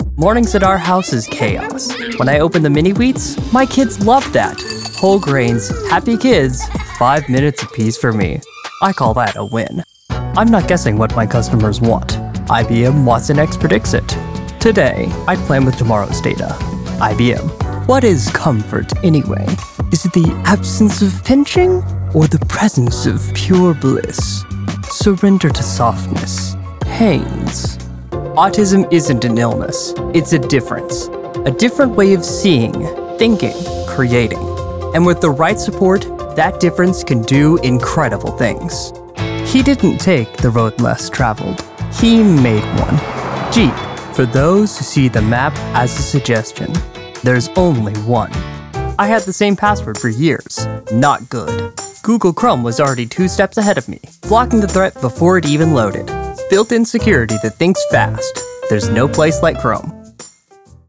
Commercial Demo
Southern and some Scottish, English, and Irish
Young Adult